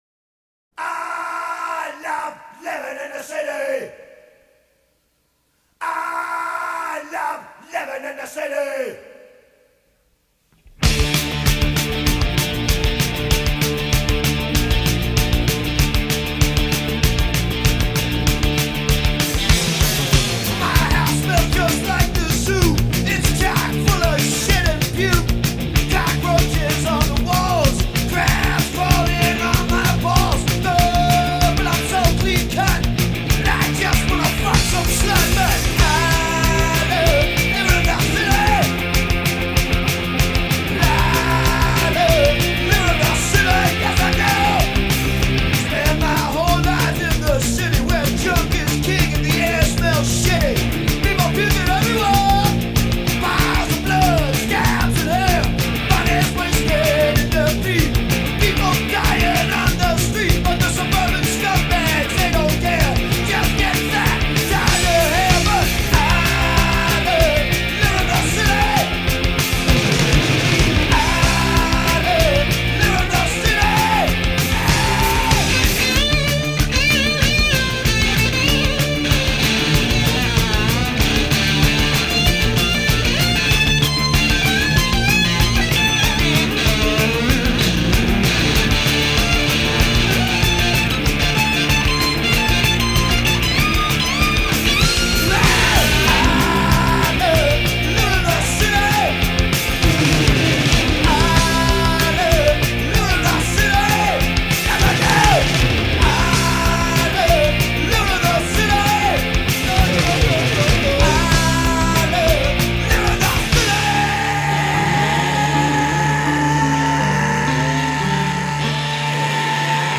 proto-hardcore
punk rock